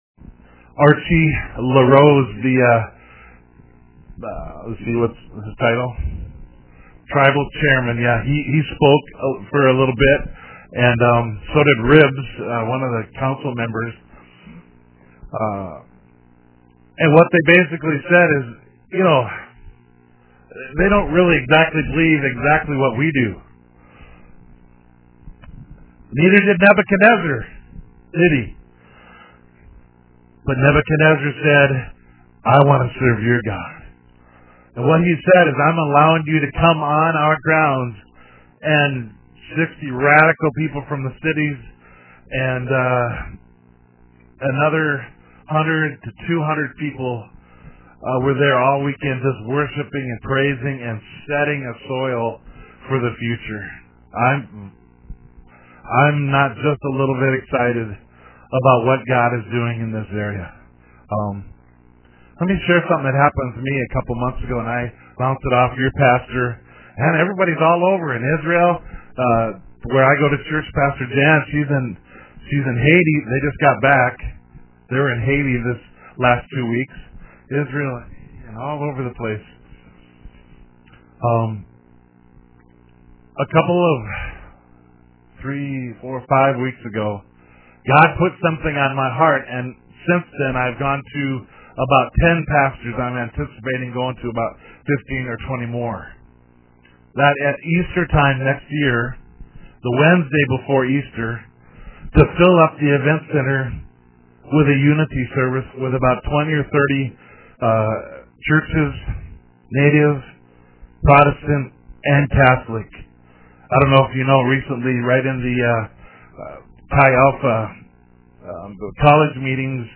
Message by guest preacher